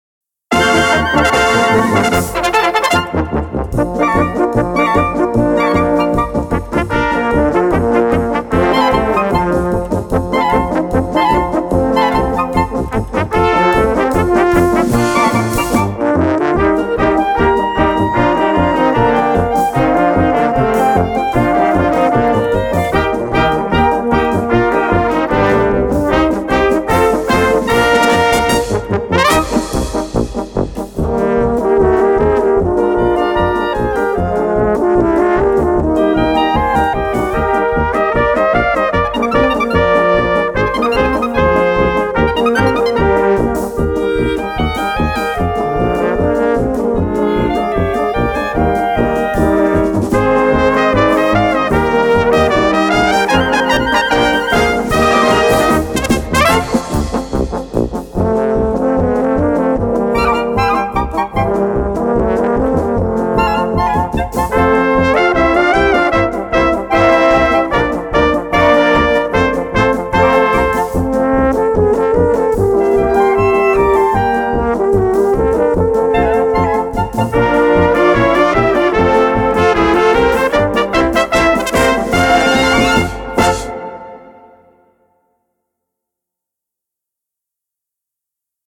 Catégorie Harmonie/Fanfare/Brass-band
Sous-catégorie Polka
Instrumentation klBlm (petite orchestre à vent)